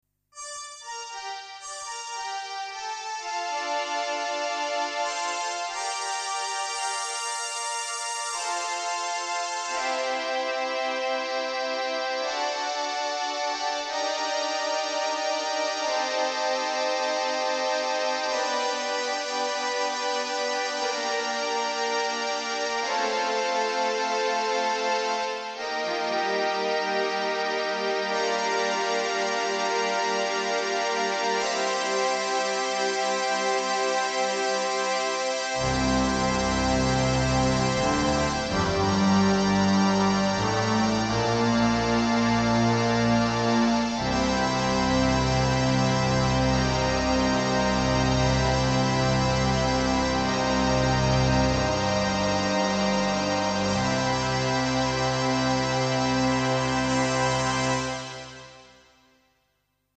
The Yamaha DX7 digital programmable algorithm synthesizer.
And yet another program of analog style sounds.